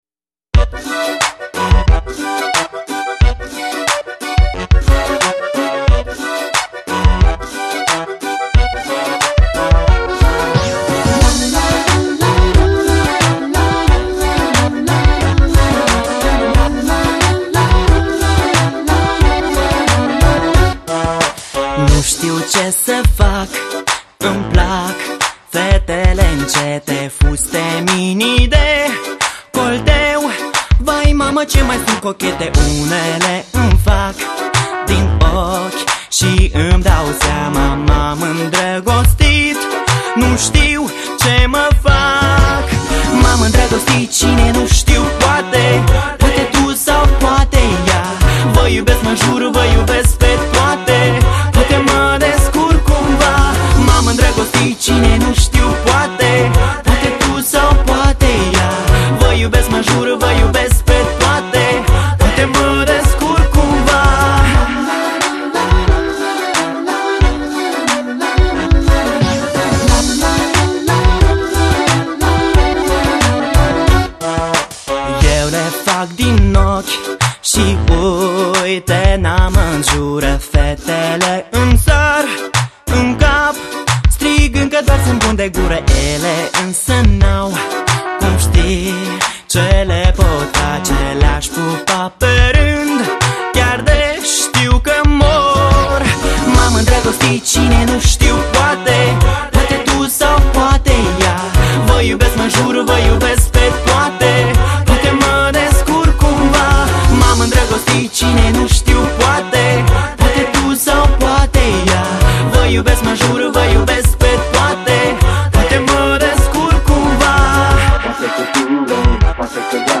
Категория: Hip-Hop